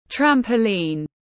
Cái bạt lò xo tiếng anh gọi là trampoline, phiên âm tiếng anh đọc là /ˈtræm.pəl.iːn/
Trampoline /ˈtræm.pəl.iːn/
Trampoline.mp3